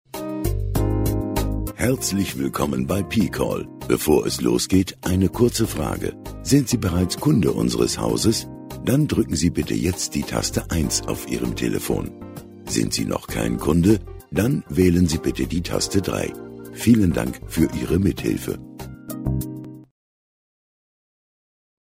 dunkel, sonor, souverän
Lip-Sync (Synchron)